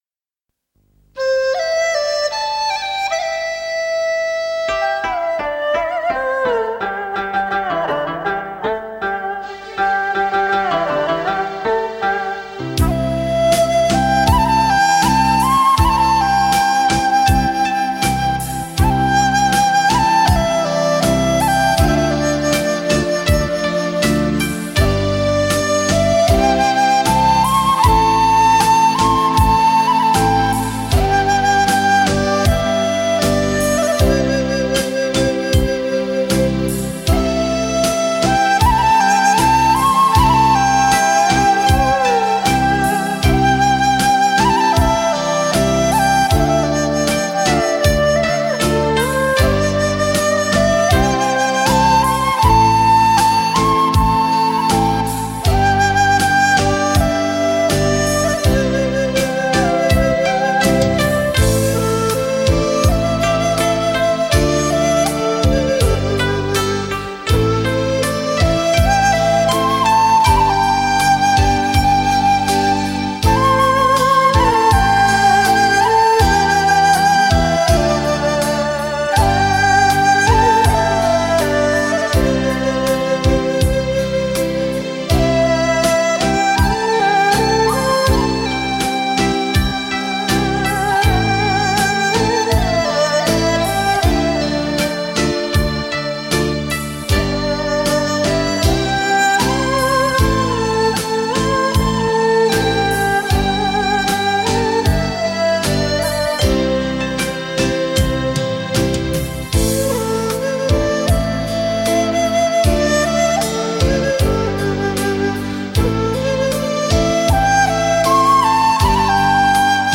竹笛，雅号横吹，发音动人，婉转，
把您带入鸟语花香或高山流水的意境之中。